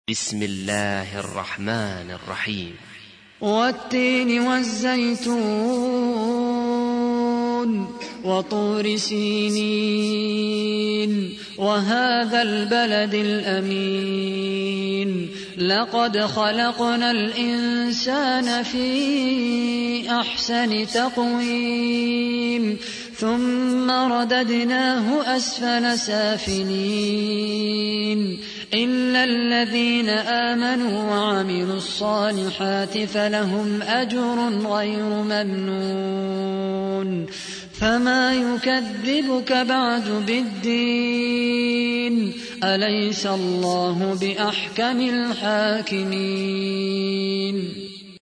تحميل : 95. سورة التين / القارئ خالد القحطاني / القرآن الكريم / موقع يا حسين